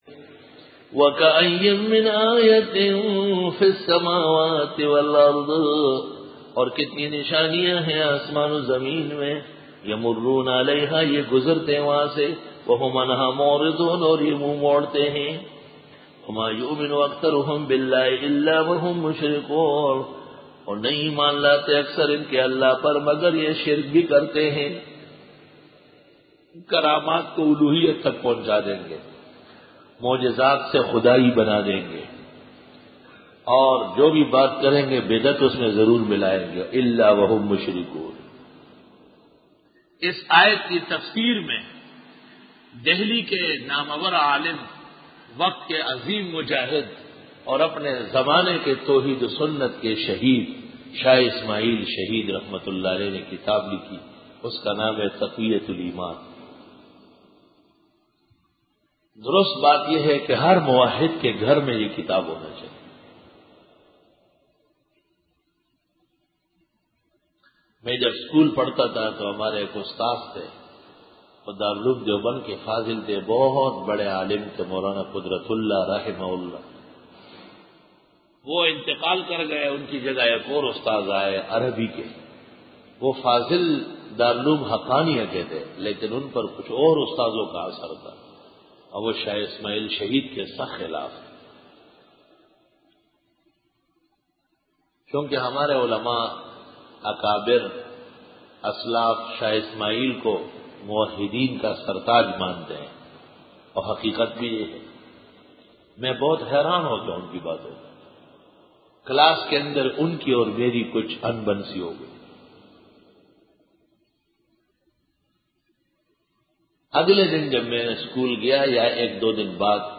Dora-e-Tafseer 2005